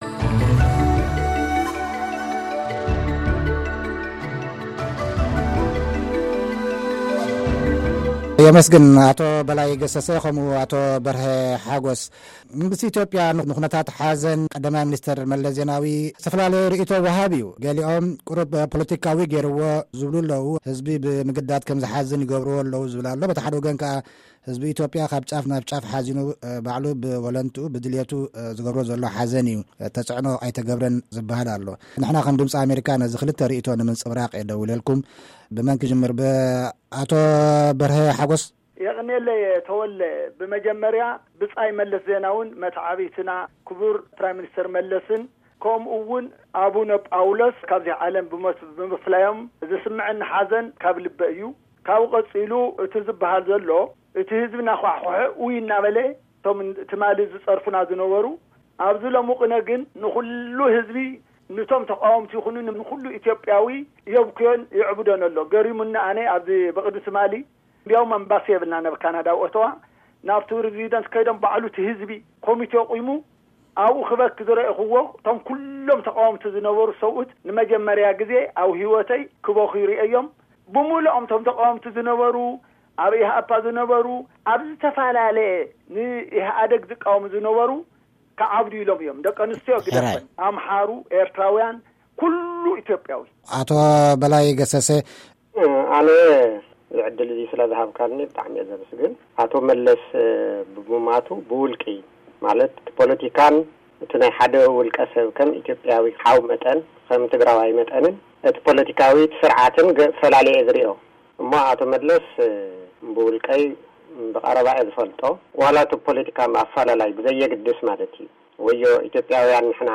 ተቓወምቲ ከይተረፈ ኣብ ንጥፈታት ስነ-ስርዓት ሓዘን ኣቶ መለስ ይሳተፉ`ለዉ ዝብልን፡ መንግስቲ ኢትዮጵያ ዝገብሮ ዘሎ ፡ ልክዕ ውልቀ-መለኽቲ መንግስታት ዝገብርዎ ስራሕ`ዩ ዝብልን፡- ክልቲኦም ዝተኻተዕሉ መደብ፡ ንምስማዕ ኣብ ምልክት ድምጺ ጠውቑ፡